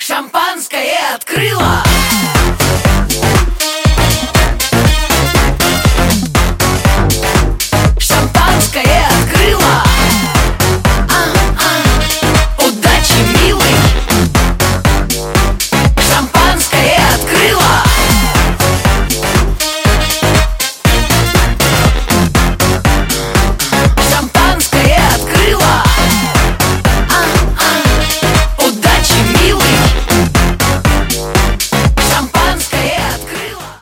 эстрада